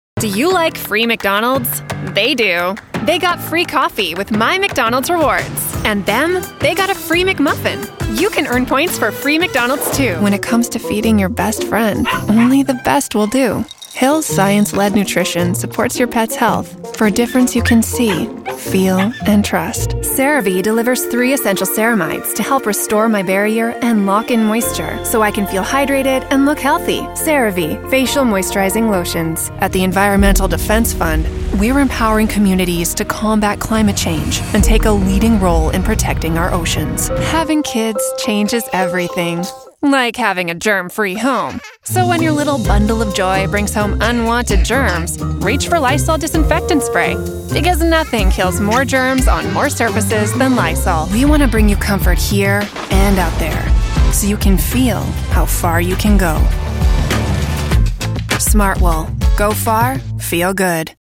Demonstração Comercial
Eu uso um microfone Neumann TLM 103, uma cabine vocal com um nível de ruído de pelo menos -60dB e uma interface de áudio Steinburg UR22C.
Jovem adulto